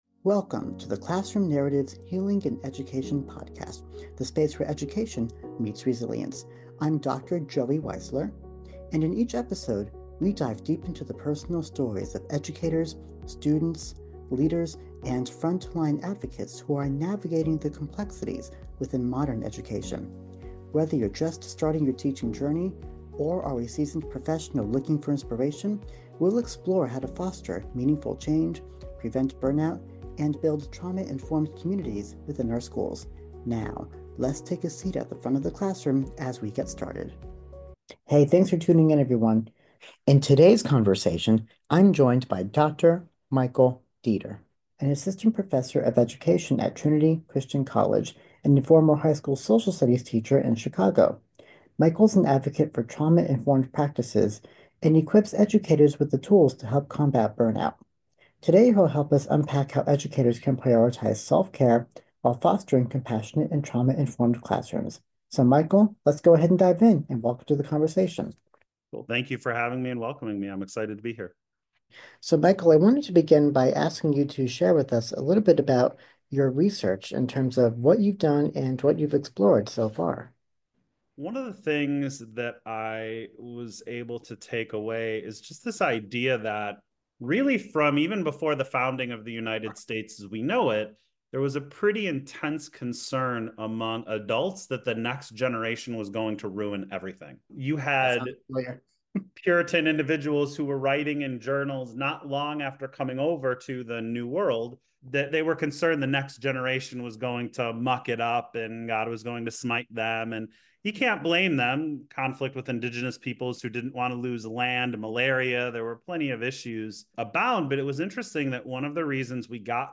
He discusses strategies for shifting from punitive discipline to trauma-informed practices, building meaningful student relationships, and balancing passion with professional boundaries. Whether you are a new teacher, a veteran educator, or simply interested in education reform, this episode offers a powerful discussion on how to create sustainable, compassionate classrooms that foster both student and teacher well-being.